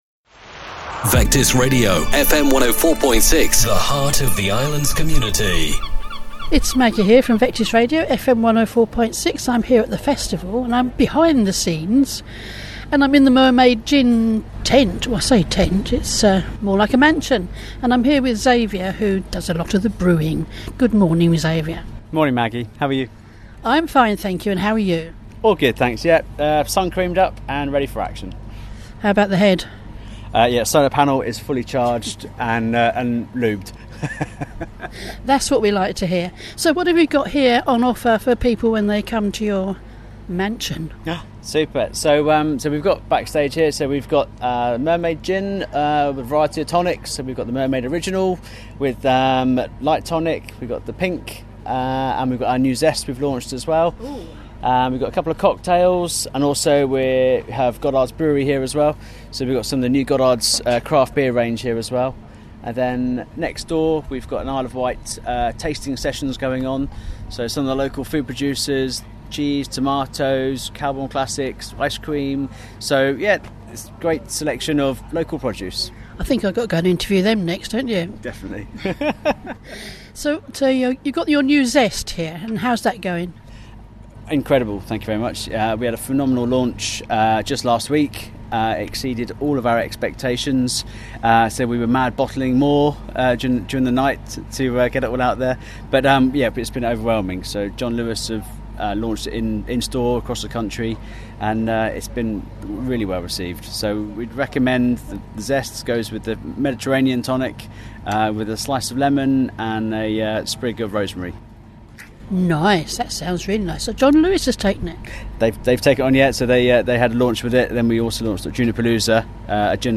Isle of Wight Festival 2022